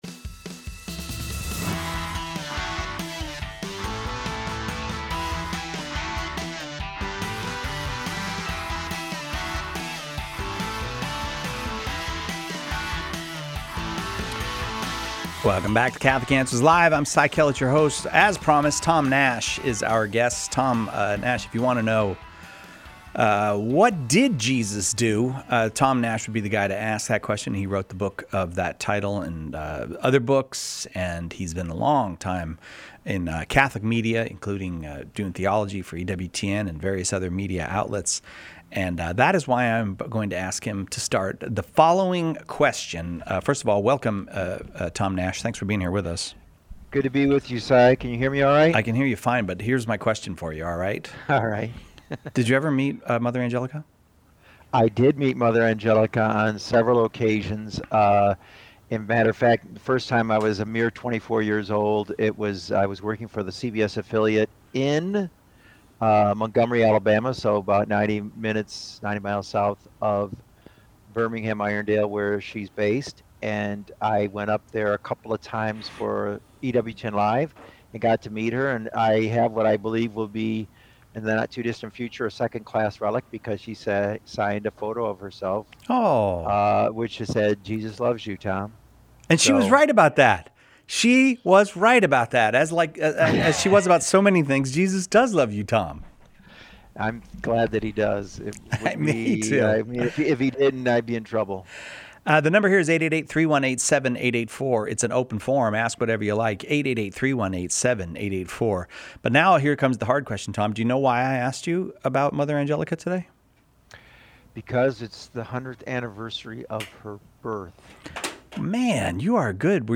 Open Forum – Questions Covered: 11:29